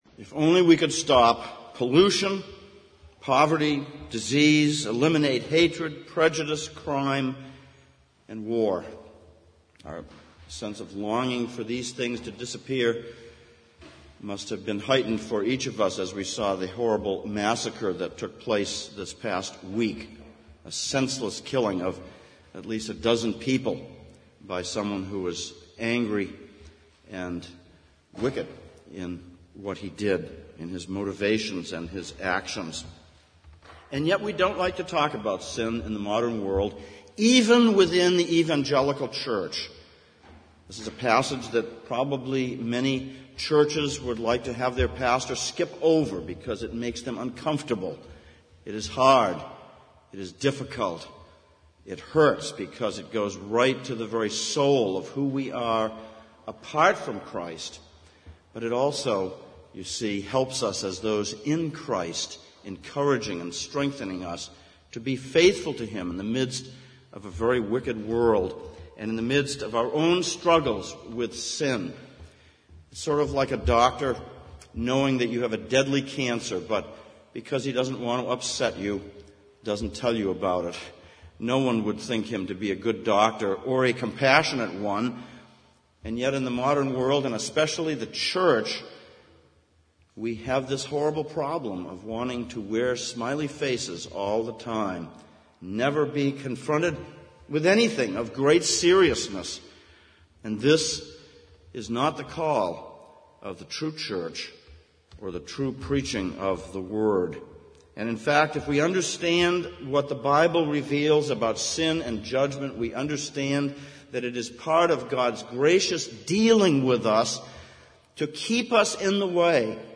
Exposition on 1 John Passage: 1 John 3:1-10, Genesis 3:1-6 Service Type: Sunday Morning Sermon ver. 1 John 3:4-6 « Purifying Hope Paradise »